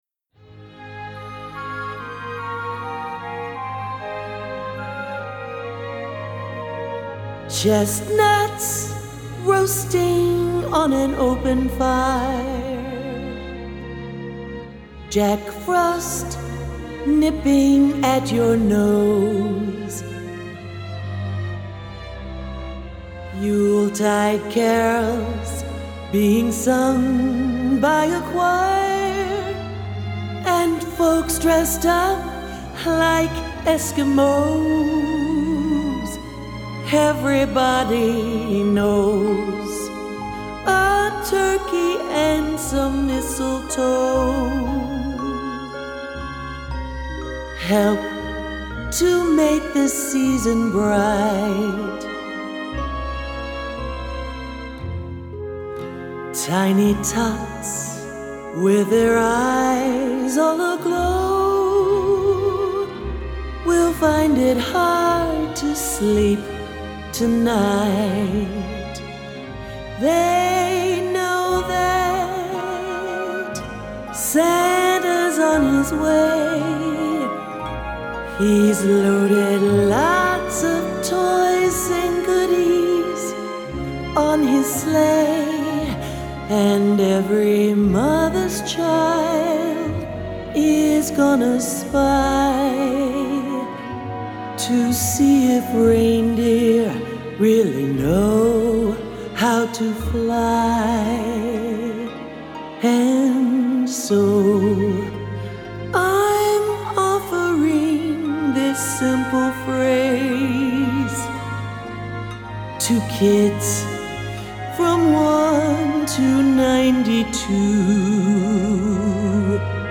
Sängerin